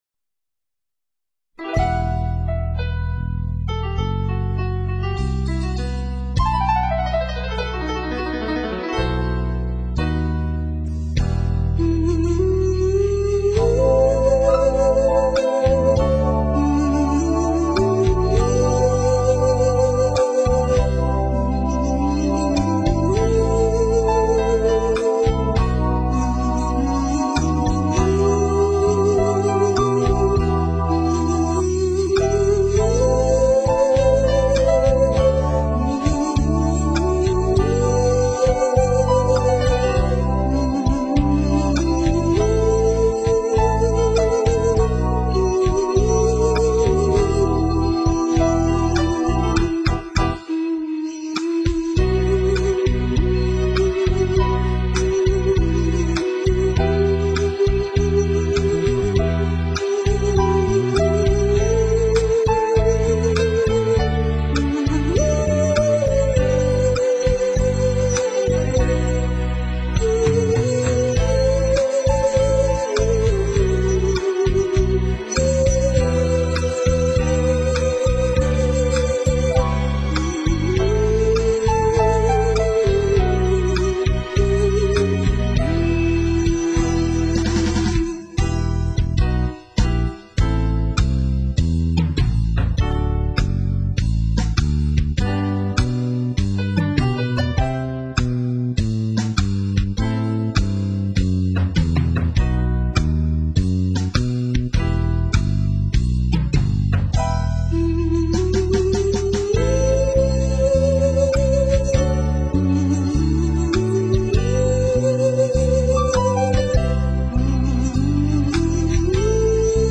幽怨柔美的旋律，其他爵士艺人得以即兴发挥的弹性空间甚大。
提琴版